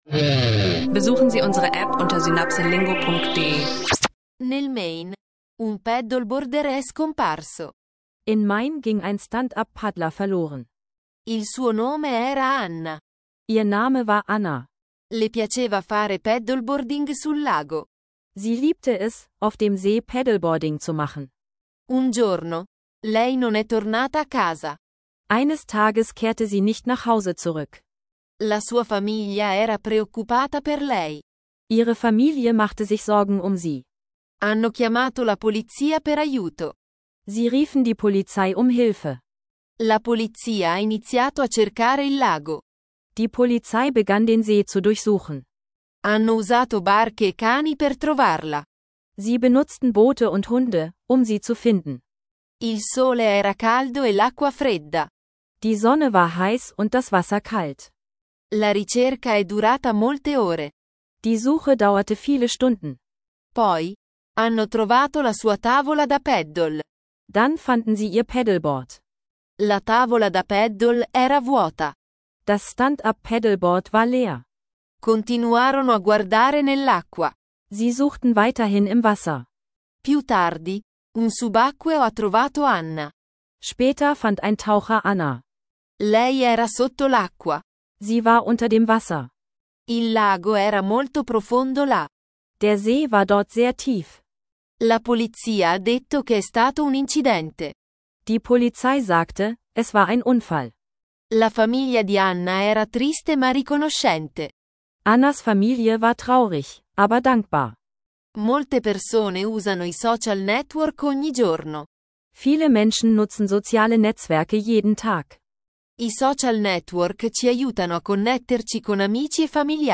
In dieser Folge trainieren wir Italienisch lernen online und hören Italienisch lernen Podcast-Dialoge zu Alltagsthemen wie Social Media, Urlaub, Ethik-Dilemmata und Sport-Neuigkeiten – perfekt für Italienisch Anfänger und Fortgeschrittene.